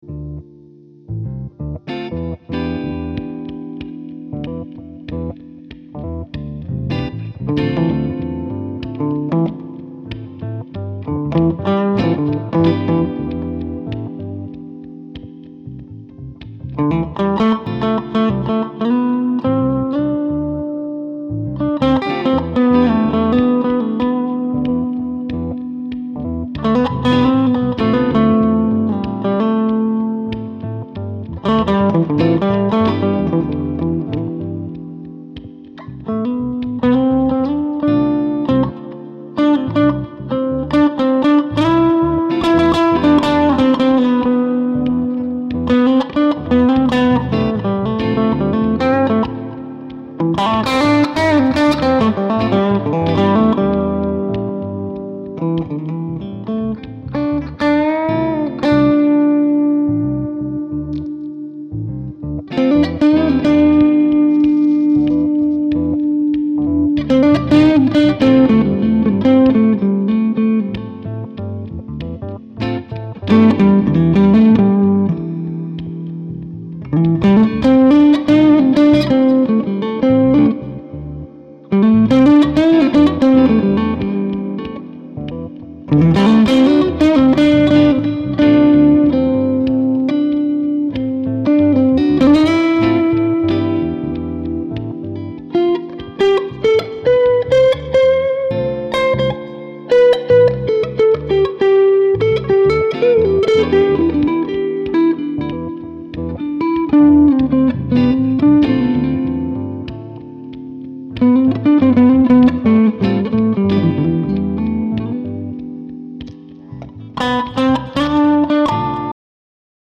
... egal, soundmäßig geht es schon in die cleane AC-Richtung..